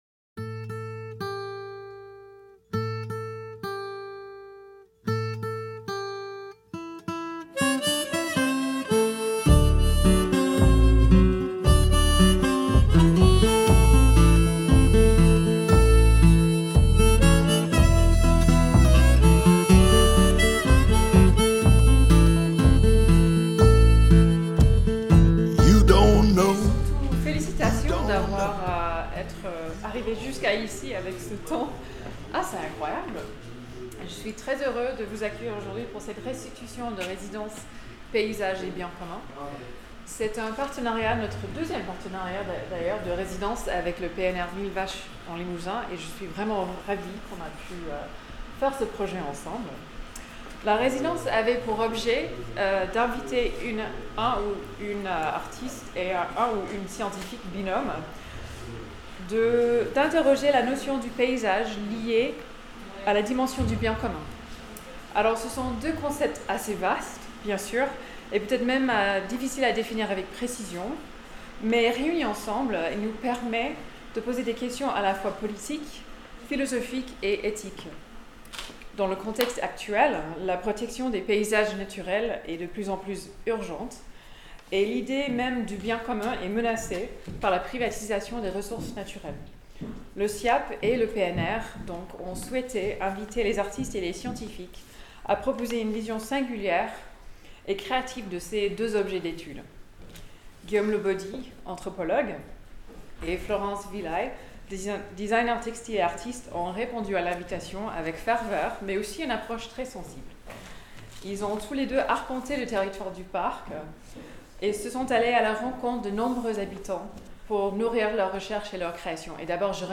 Reportage au CIAP :: Paysage et bien être commun - Radio Vassivière